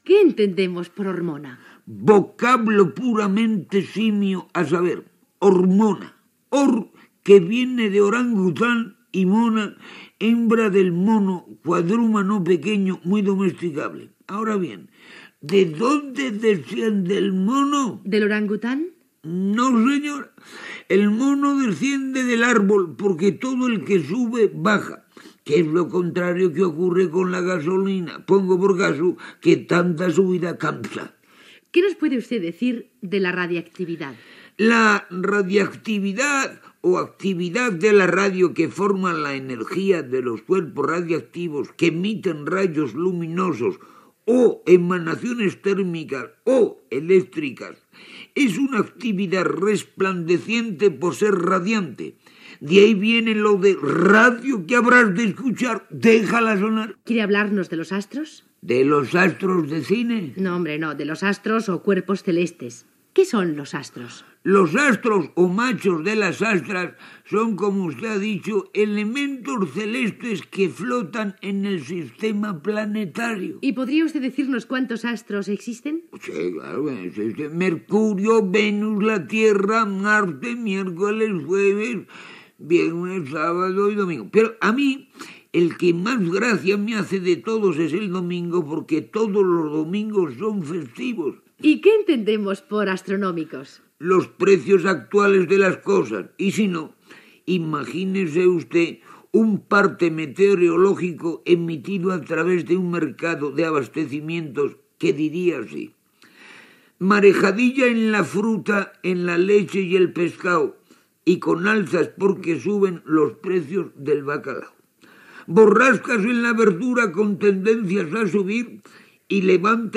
Preguntes científiques al personatge Don Anselmo Carrasclás, interpretat per Tony Leblanc (Ignacio Fernández Sánchez)
Entreteniment